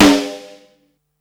• 2000s Roomy Snare Sample G Key 76.wav
Royality free snare one shot tuned to the G note. Loudest frequency: 1475Hz